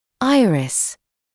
[‘aɪərɪs][‘айрис]радужка; радужная оболочка глаза